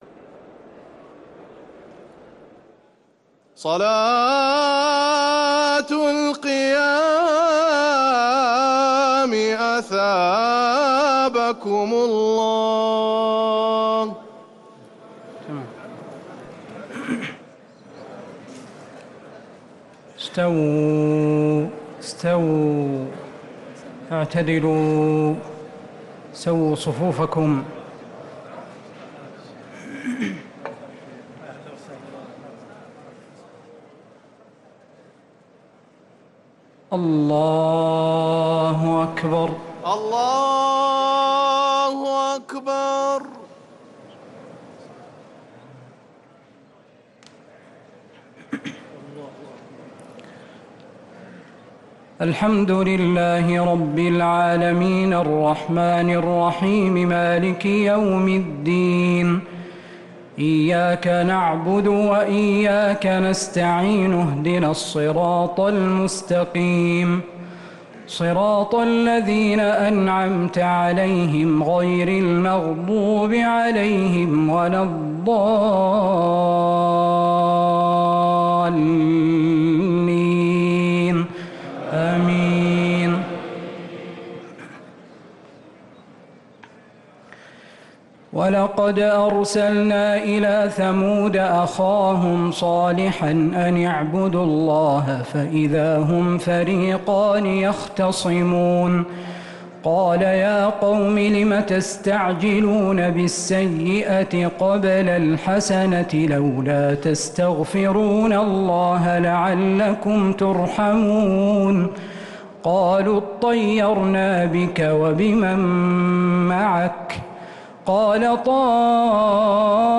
صلاة التراويح ليلة 23 رمضان 1445
الثلاث التسليمات الاولى صلاة التهجد